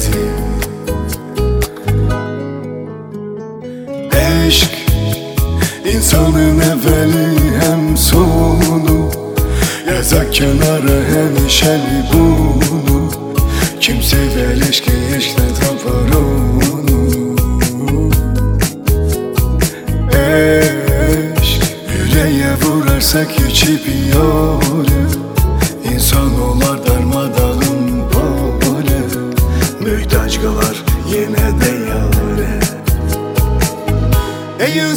Жанр: Танцевальные / Поп